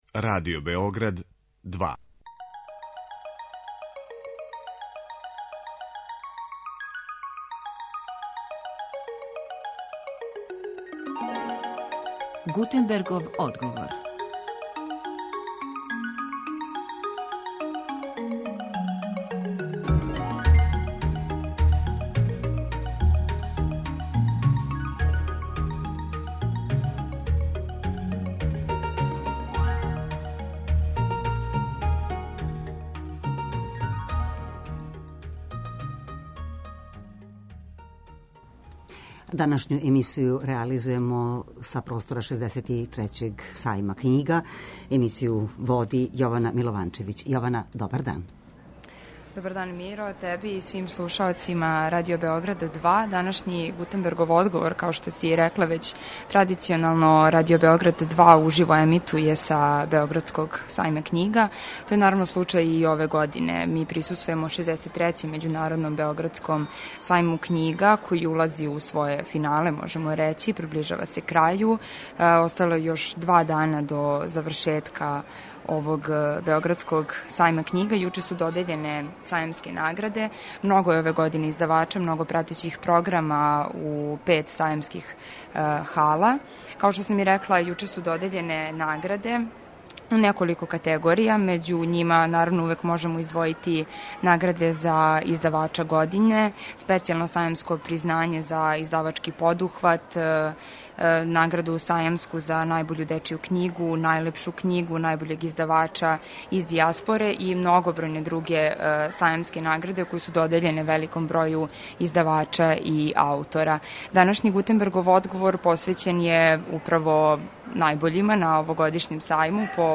Уживо са сајма
Данашњи Гутенбергов одговор реализујемо уживо са 63. Међународног београдског сајма књига, који протиче под слоганом „Радост читања” . Гости ће бити добитници овогодишњих сајамских признања: за најбољег издавача, издавачки подухват и дечју књигу године.